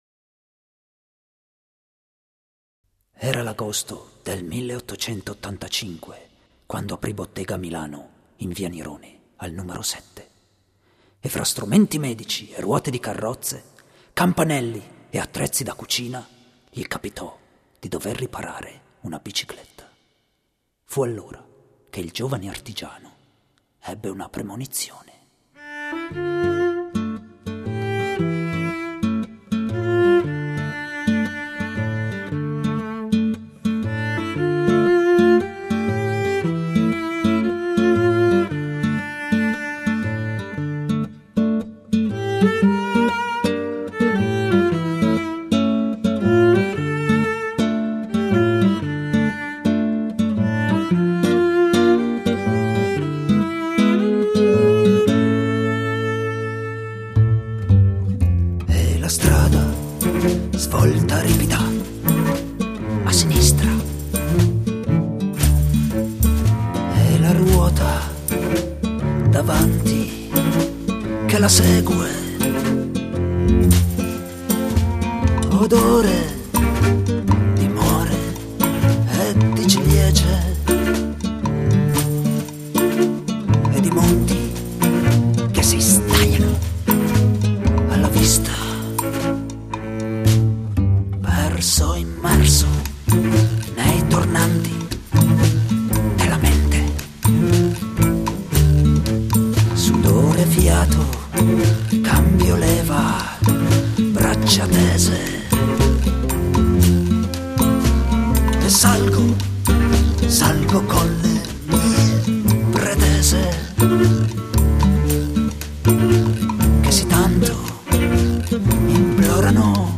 violoncello
violino
fisarmonica
voce, chitarra classica/acustica